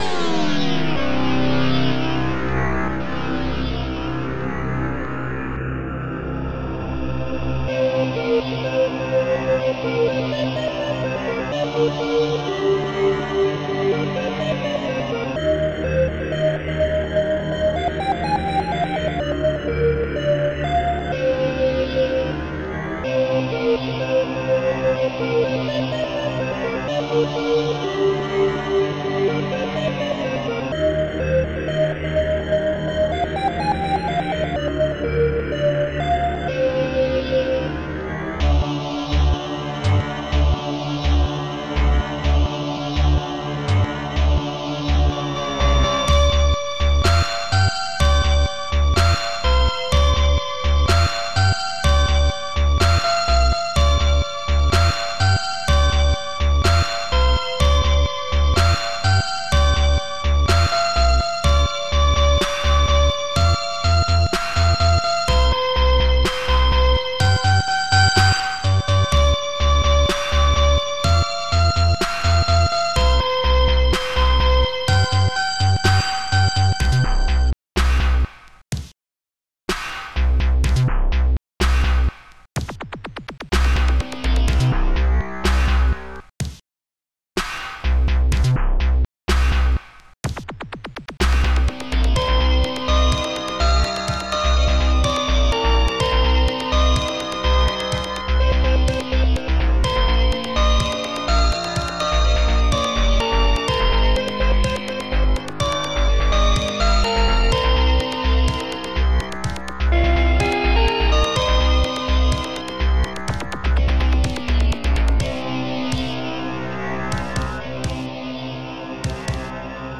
Protracker and family
st-70:snaredrum
st-70:HallBrass
st-70:bassdrum
st-01:KorgBass
st-01:HiHat2
st-01:Claps1